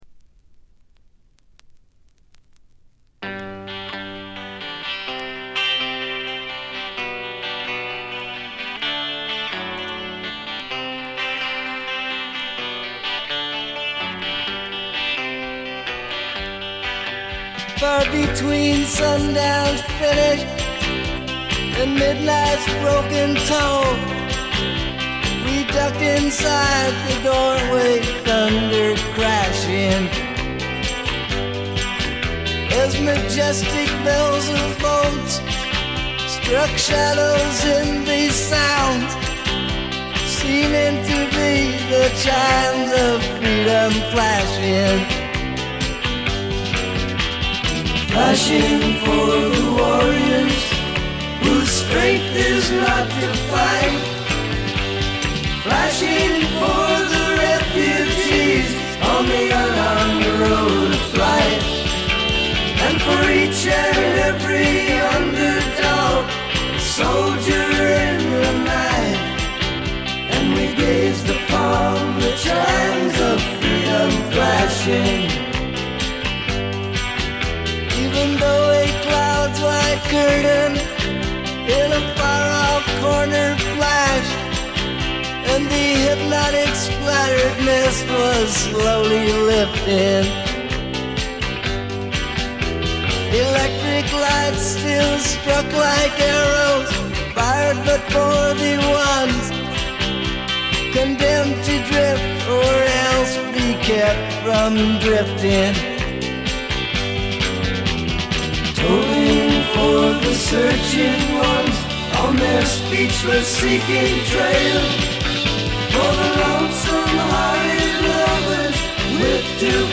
バックグラウンドノイズは出てはいますが気にならないレベルです。
モノラル。
試聴曲は現品からの取り込み音源です。